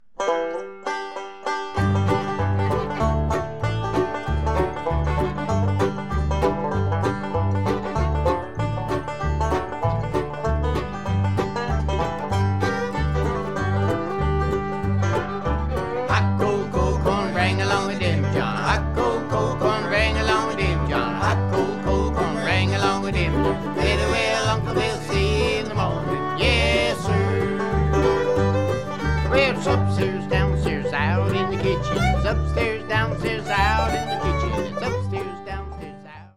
Band version (key of G)